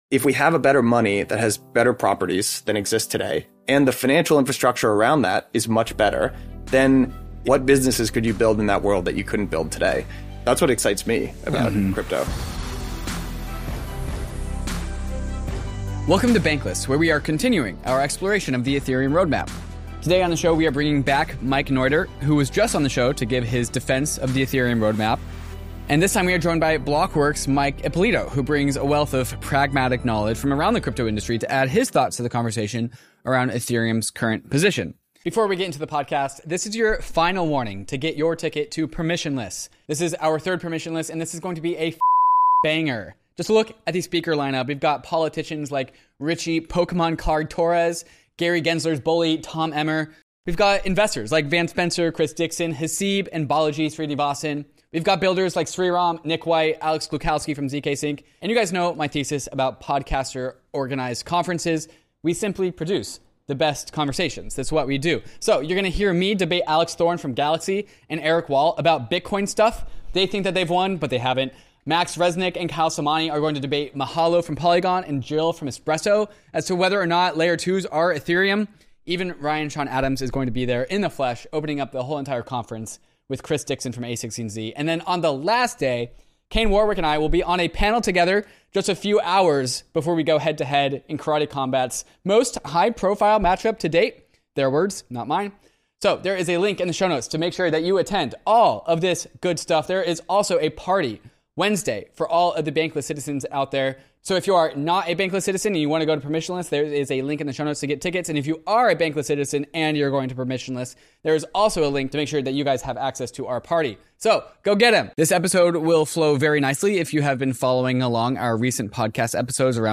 They delve into rollup technology, value accrual mechanisms, and the philosophical differences between Ethereum and emergent competitors like Solana. The conversation resonates with bullish optimism, discussing what it takes for Ethereum's promises to become reality and addressing the potential impacts of scalability and governance on its future. 01:18:05 share Share public Creator website